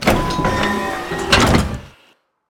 ElevatorOpen1.ogg